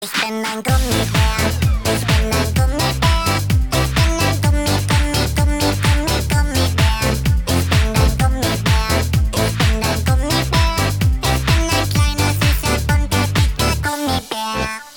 прикольные
детский голос